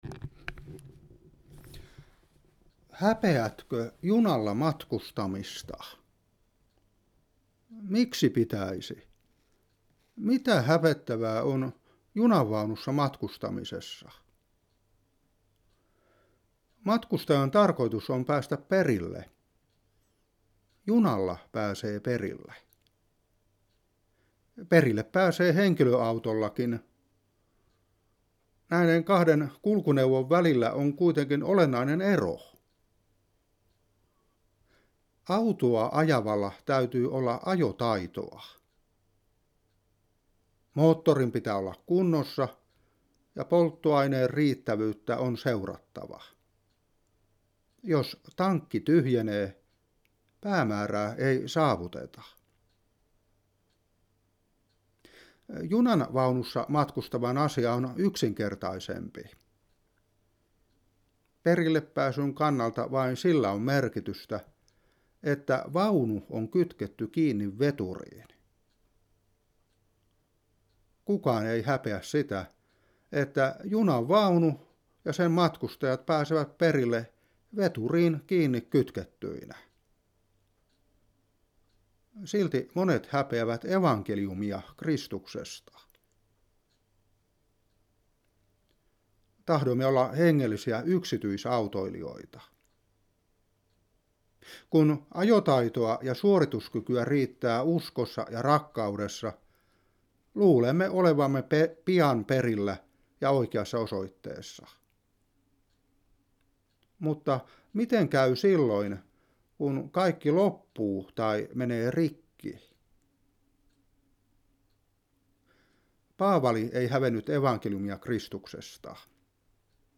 Hartaus